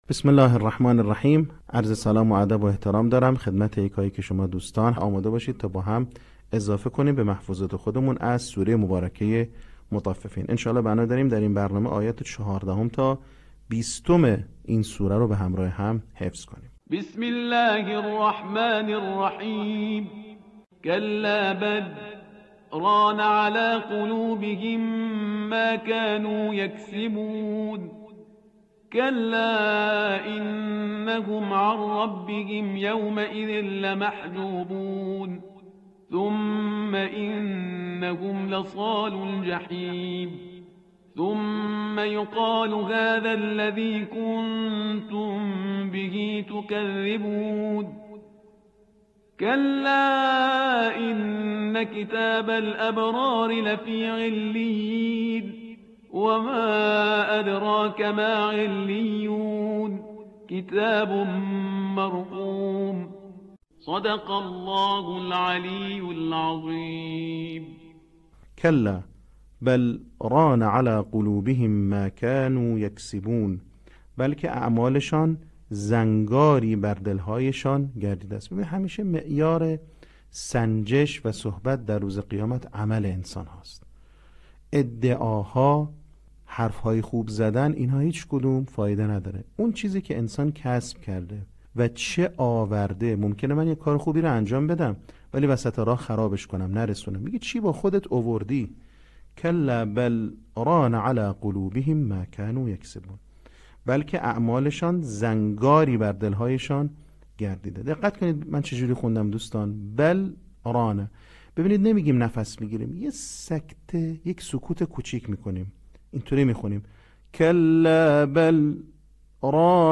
صوت | بخش سوم آموزش حفظ سوره مطففین